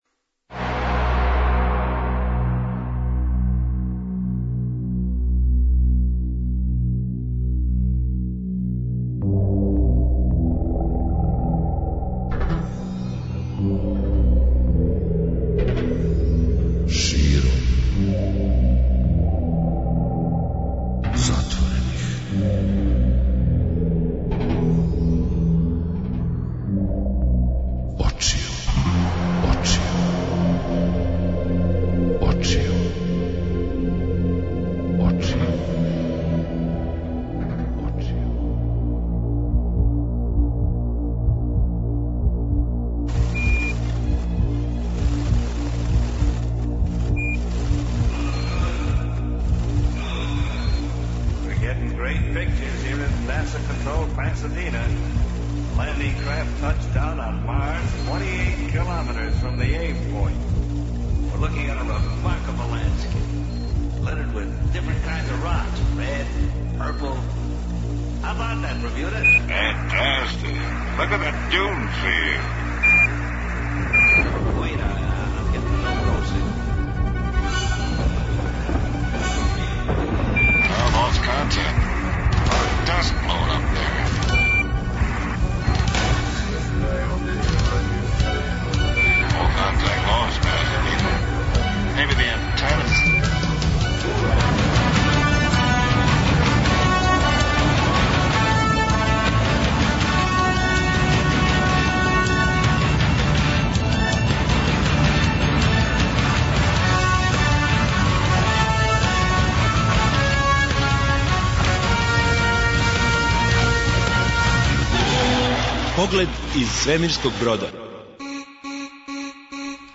Чућете о чему је говорио на две трибине, али и две ексклузиве које је открио онима који су дошли да га слушају.